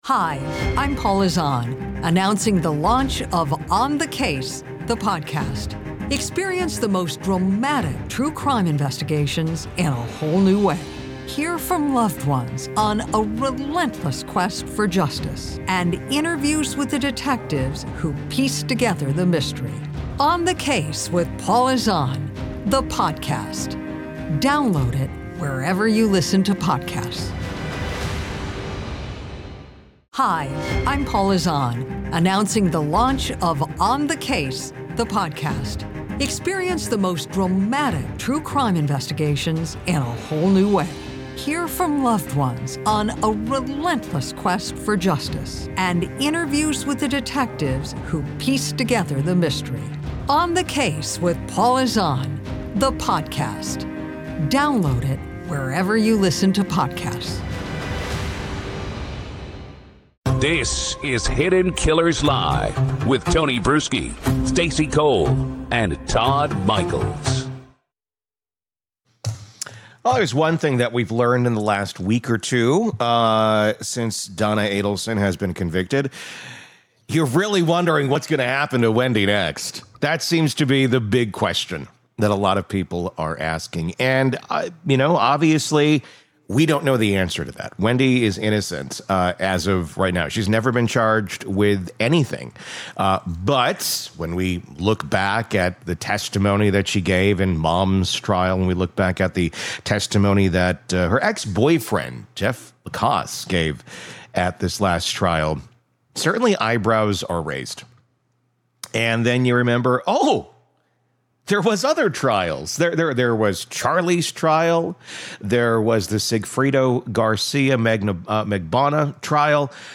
In this first segment, the Hidden Killers team sets the tone with a mix of sharp commentary and off-the-wall humor.
This opener is chaotic, funny, and unsettling, a snapshot of how dark crime stories bleed into the absurd.